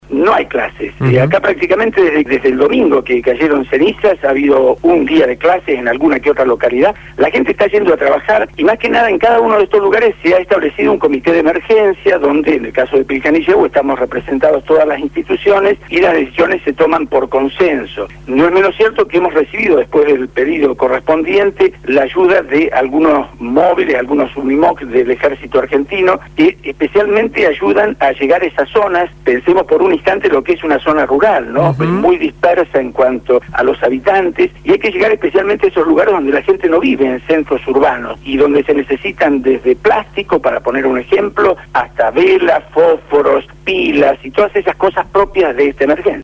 INFORME DESDE LA PATAGONIA: LA ERUPCIÓN DEL VOLCÁN PUYEHUE EN CHILE